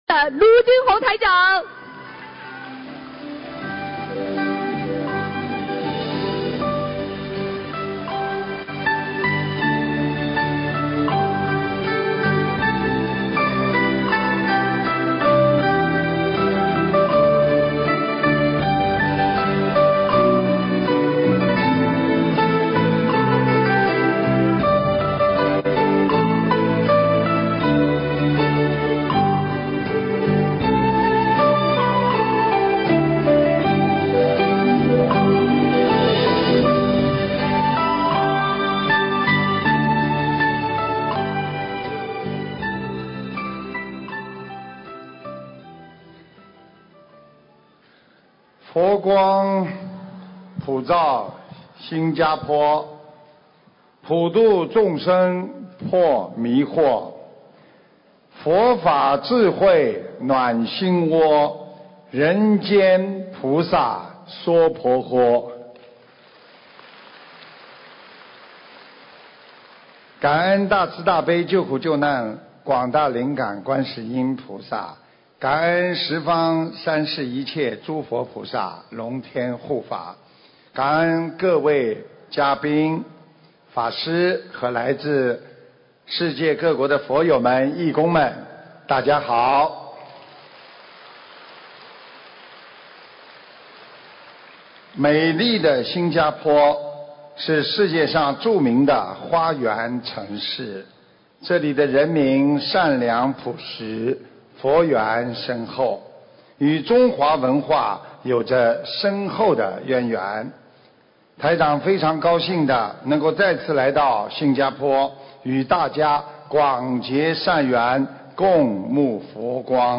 【师父开示】